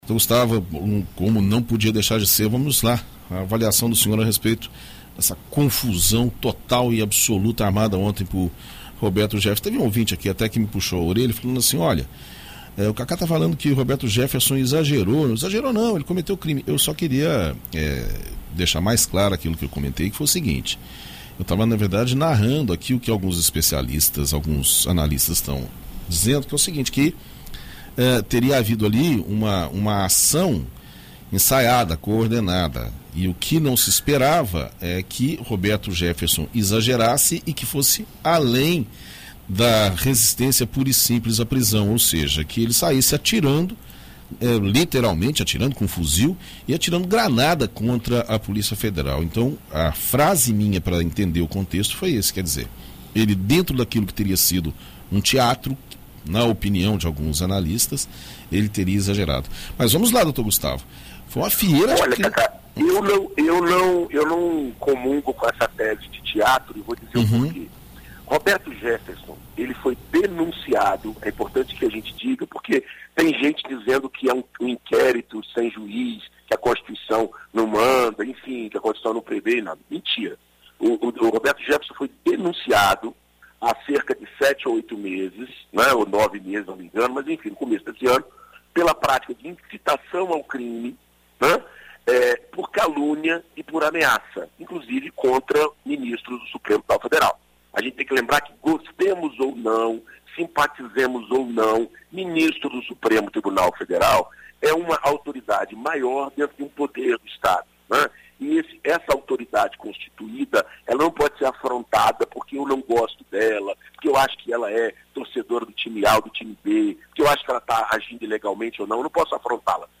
Direito para Todos: advogado analisa situação envolvendo Roberto Jefferson